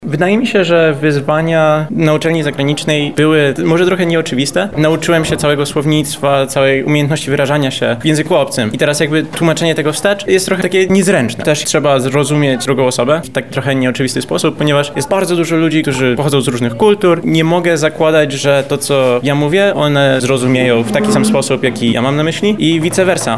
Podczas VIII edycji Zimowych Warsztatów Otwartych Kół Olimpijskich zapytaliśmy jednego z prelegentów o wyzwania, z jakimi mierzył się podczas studiów zagranicznych.